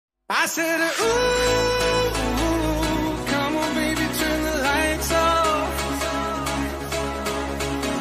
turn the lights off Meme Sound Effect
The turn the lights off meme sound effect is widely used in Instagram Reels, YouTube Shorts, gaming videos, and funny meme edits. This sound is perfect for adding humor, surprise, or dramatic timing to your content.